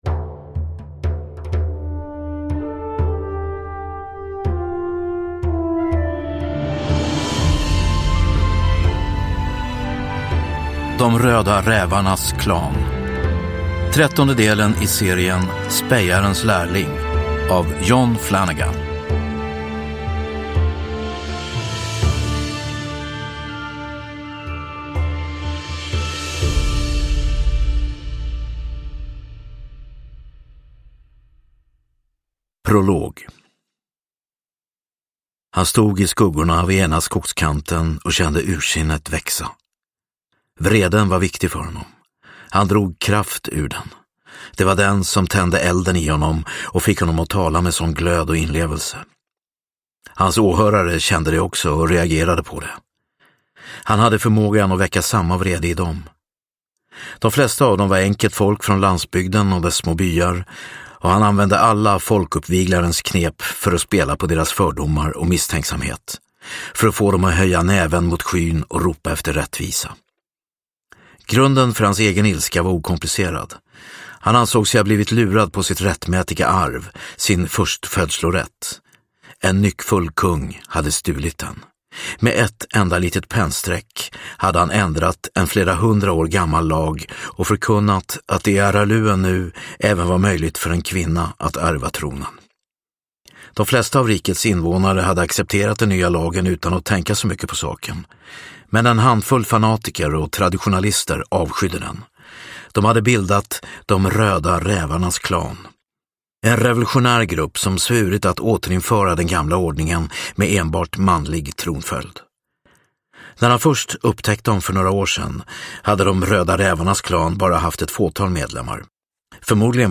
De röda rävarnas klan – Ljudbok – Laddas ner